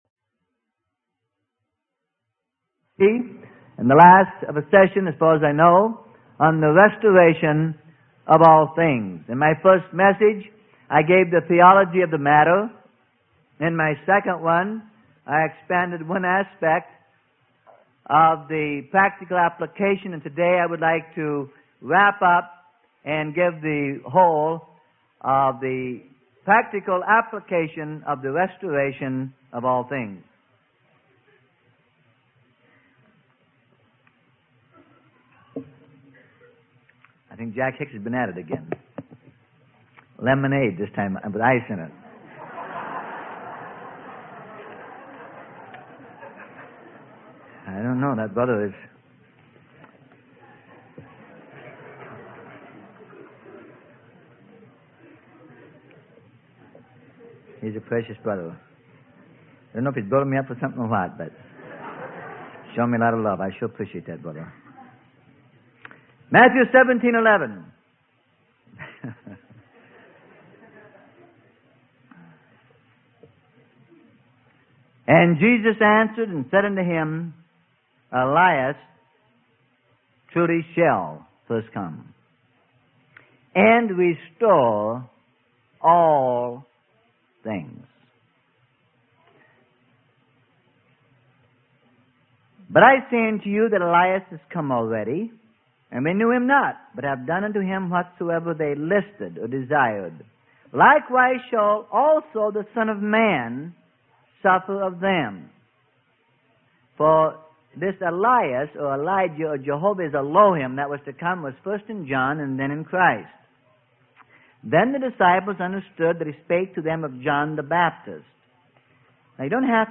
Sermon: The Coming Restoration - Part 3 - Freely Given Online Library